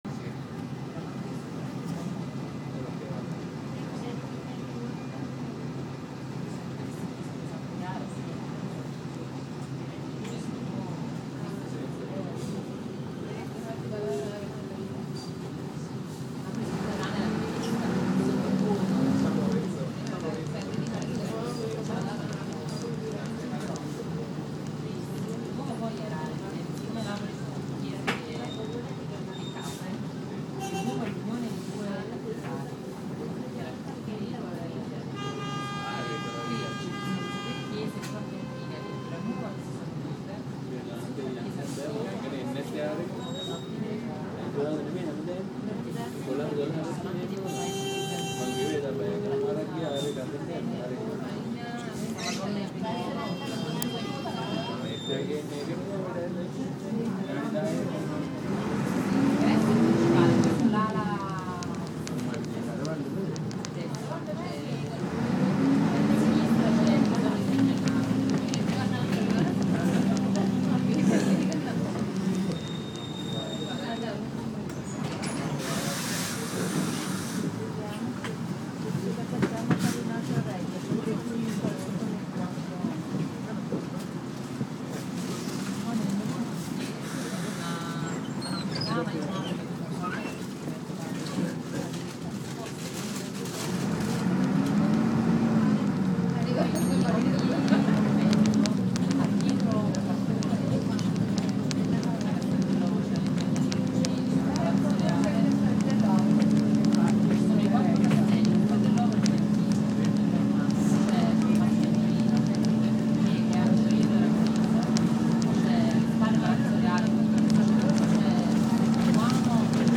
Redescendant du musée par le bus R4 sous la pluie battante et dans l’embouteillage du samedi après-midi via Toledo, à hauteur de la Piazza Dante, j’ai activé l’enregistreur du iphone et saisi quelques voix (et klaxons et tambourinement des gouttes) de Naples.